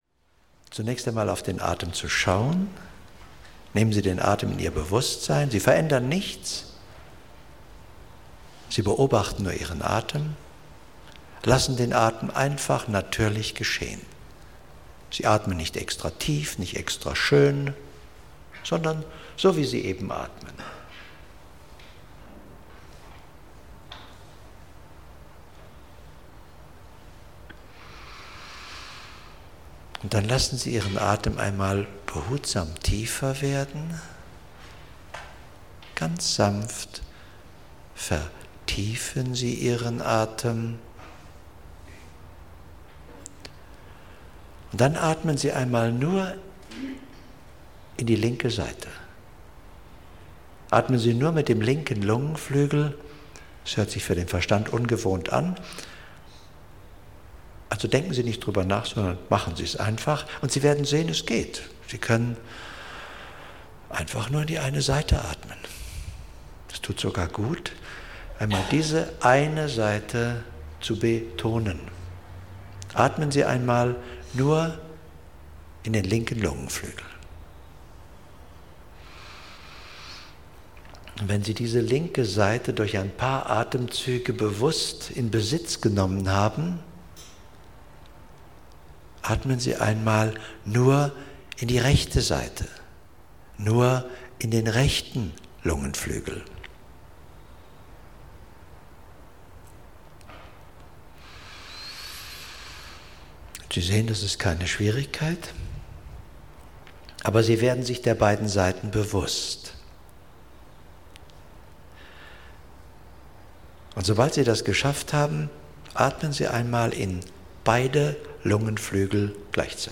Long-Seminar-Classics - Die Kraft der schöpferischen Imagination - Hörbuch